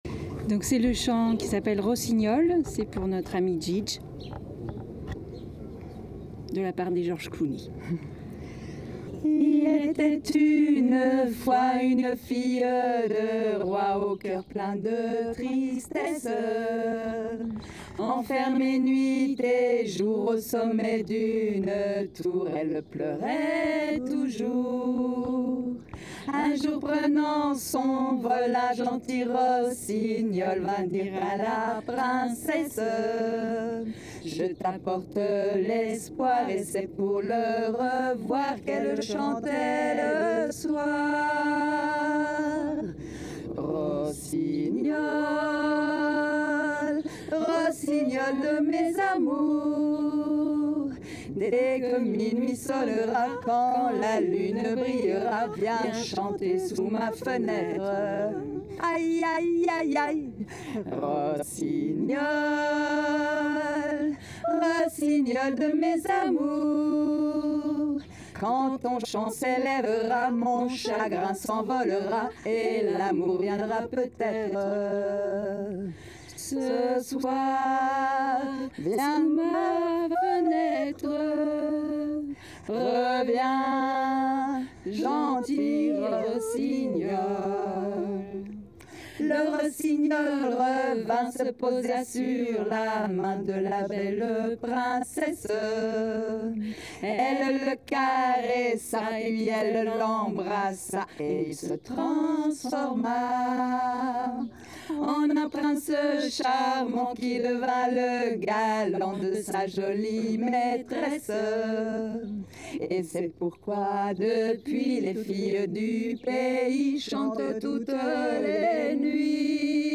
Thonon-les-bains // été 2019 Festival les Fondus du Macadam
chant en français